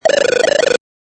warn.mp3